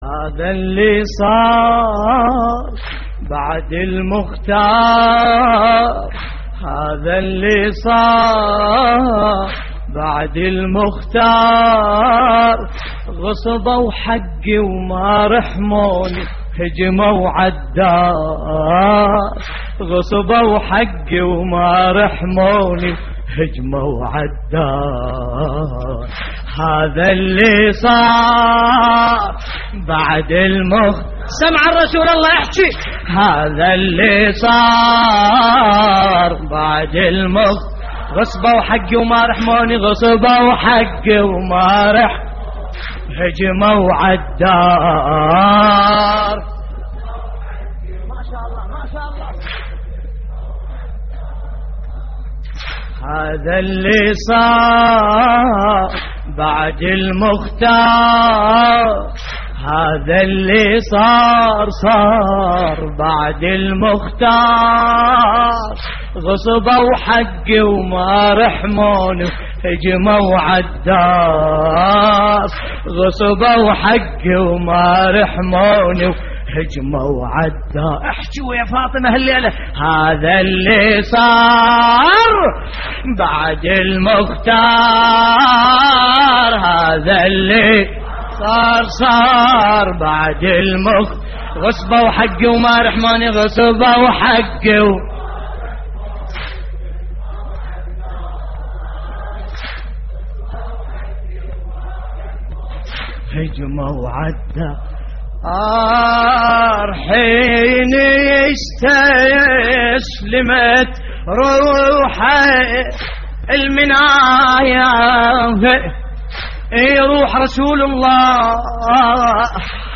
تحميل : هذا اللي صار بعد المختار غصبوا حقي / الرادود باسم الكربلائي / اللطميات الحسينية / موقع يا حسين